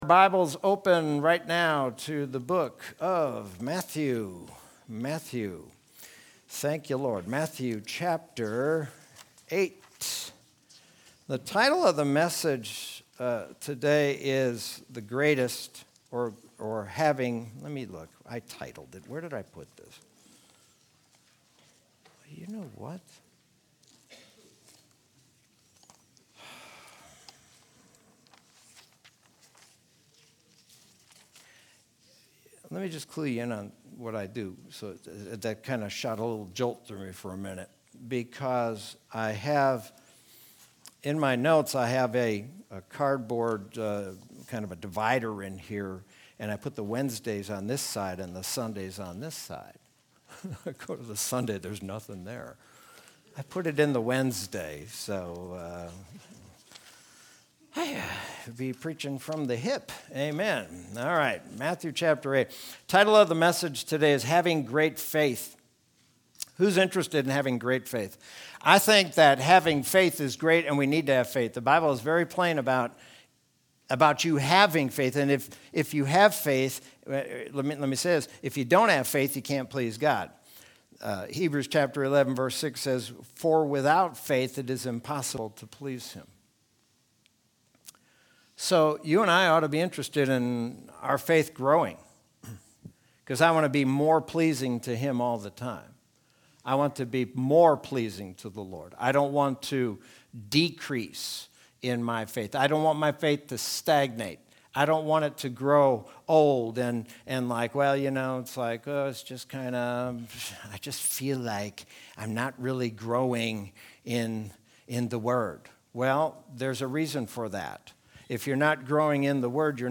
Sermon from Sunday, May 23rd, 2021.